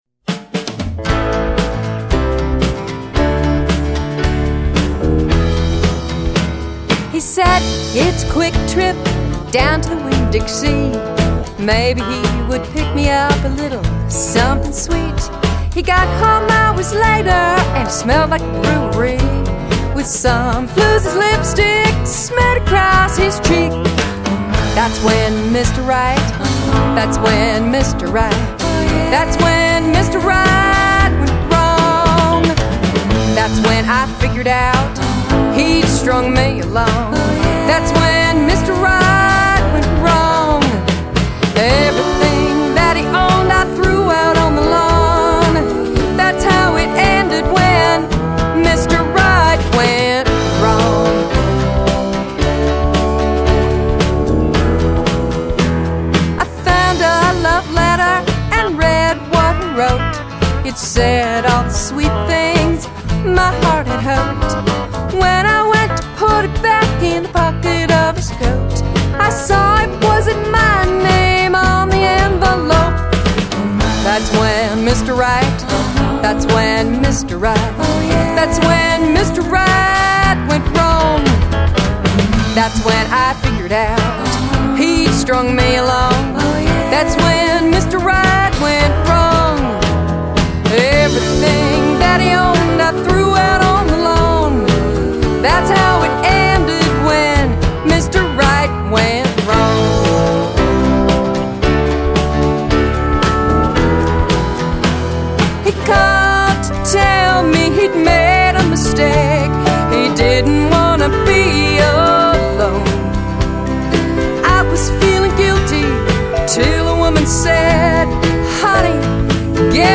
Final CD mix
Drums
Keyboards
MSA pedal steel, bass, Telecaster guitar